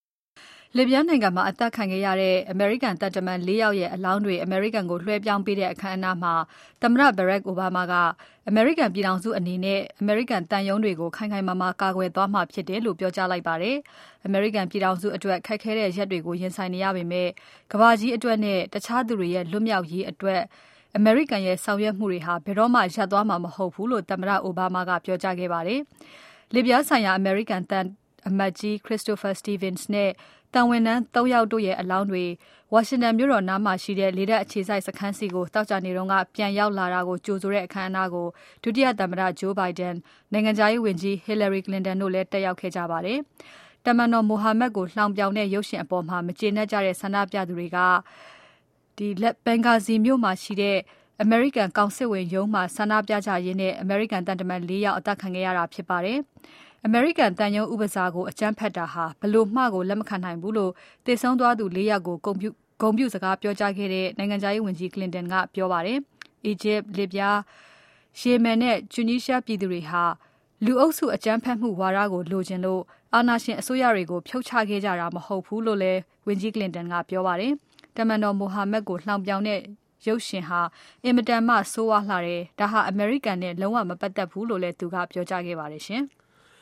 Obama speaks during the Transfer of Remai